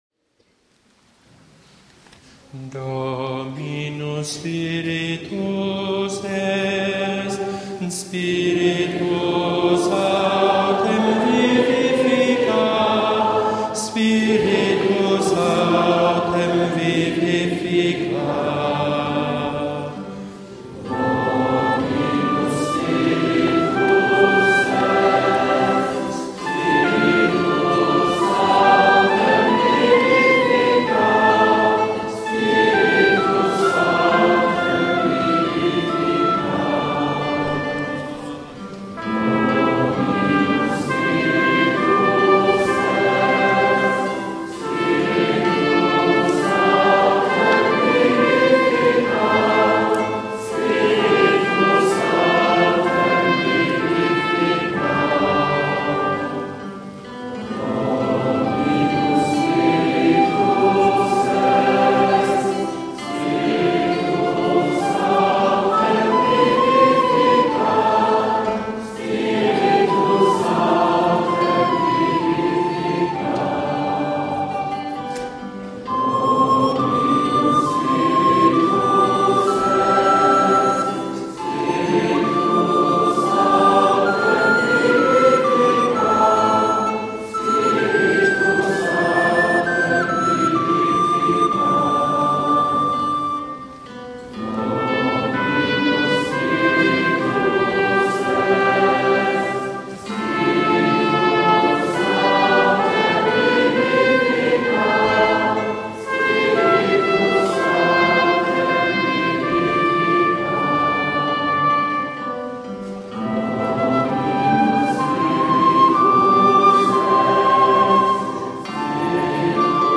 spievaná modlitba